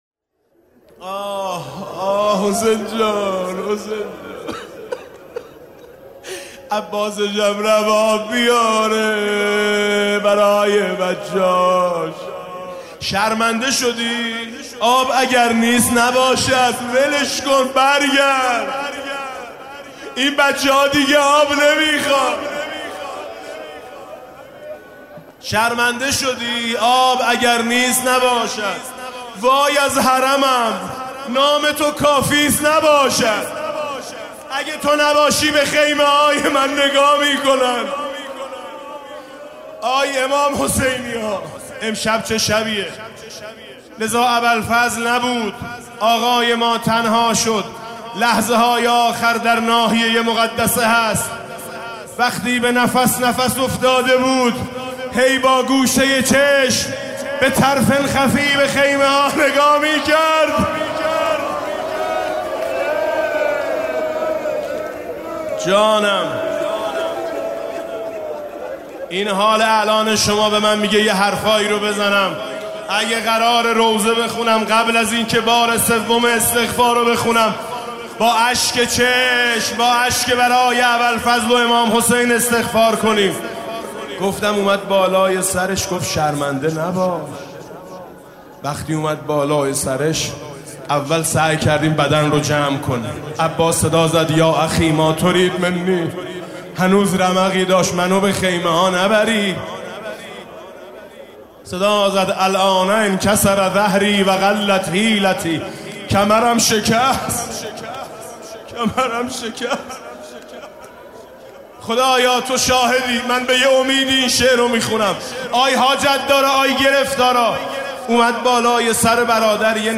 مكان: تهران، میدان شيخ بهایی، ده ونک
امامزاده قاضی الصابر (علیه‌السّلام)
روضه حضرت ابوالفضل